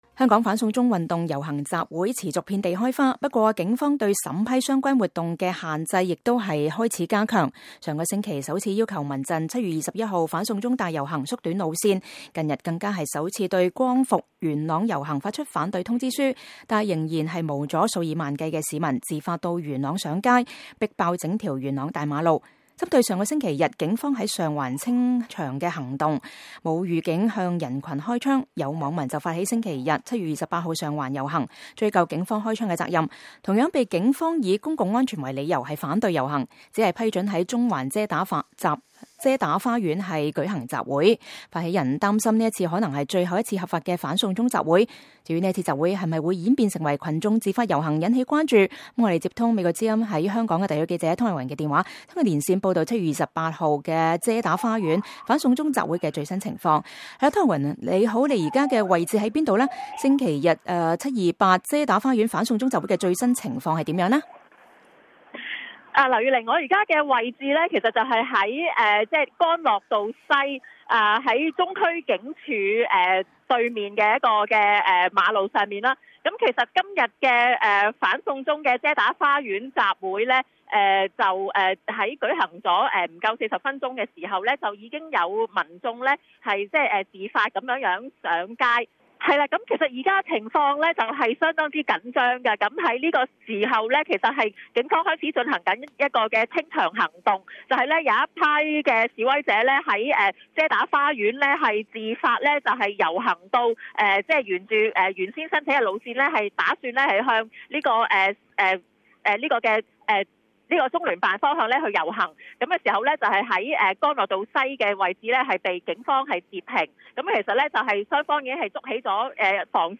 香港反送中遮打花園集會 追究警方開槍責任 - 現場報道